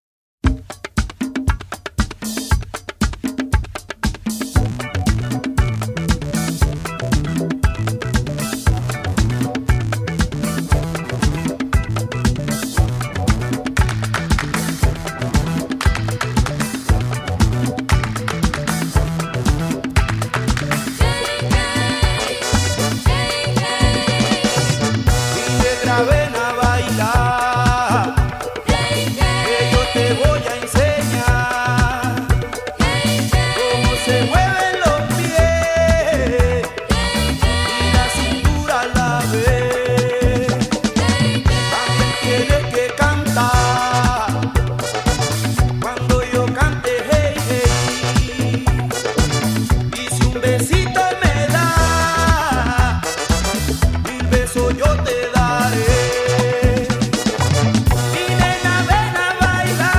A funky slice of tropical Afro-Latin fusion